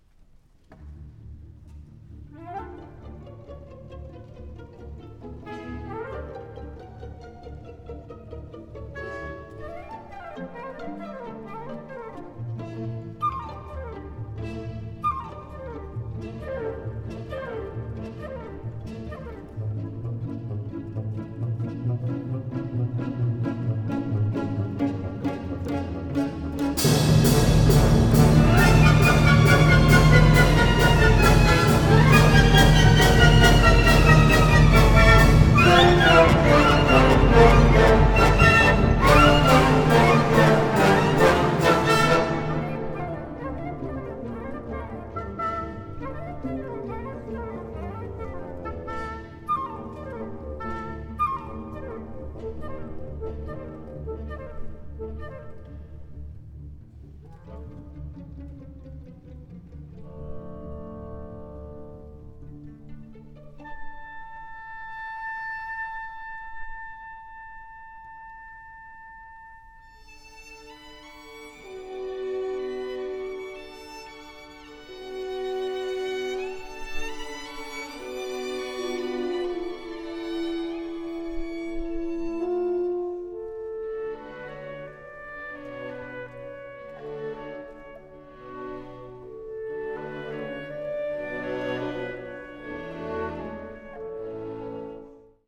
Extraits du concert